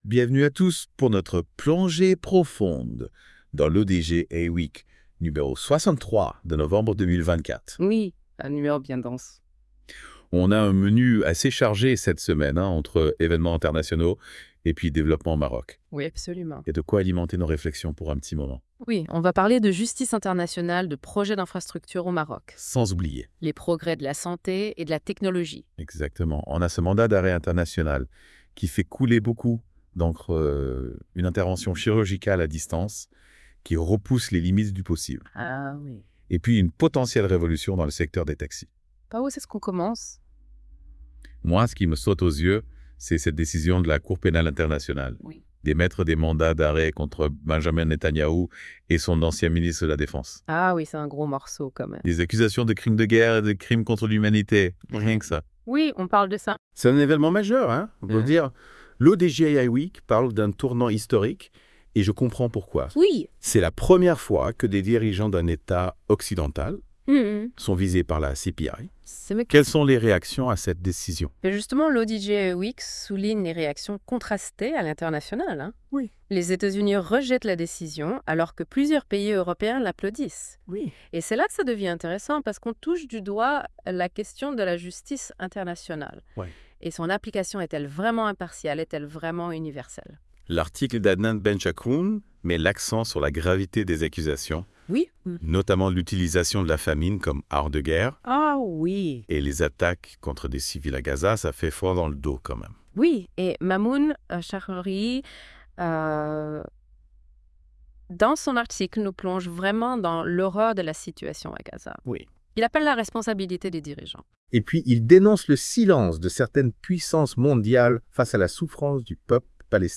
Podcast débat - I-WEEK N63.wav (61.07 Mo)